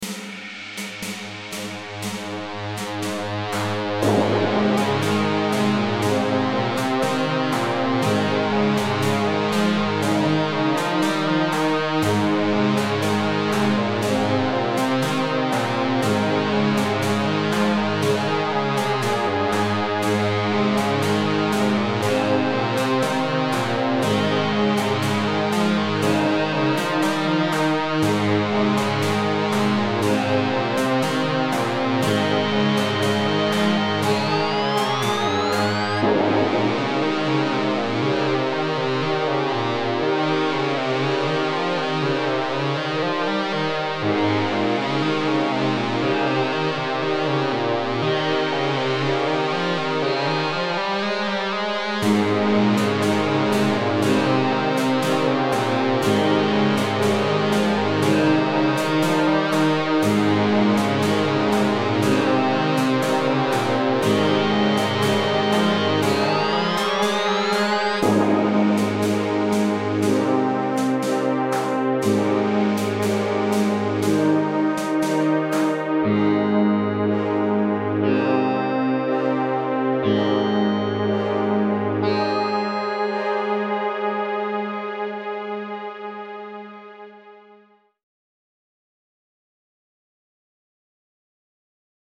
Guitar Riffs
guitarriffs.mp3